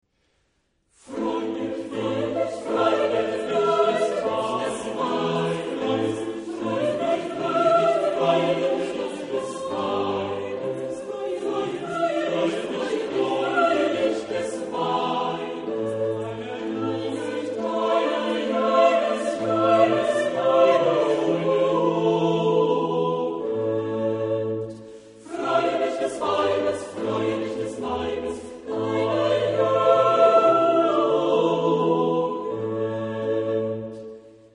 Genre-Stil-Form: geistlich ; Barock ; Motette
Chorgattung: SSATB  (5 gemischter Chor Stimmen )
Instrumentation: Continuo  (1 Instrumentalstimme(n))
Instrumente: Cembalo (1) oder Orgel (1)
Tonart(en): a-moll
von Dresdner Kammerchor gesungen unter der Leitung von Hans-Christoph Rademann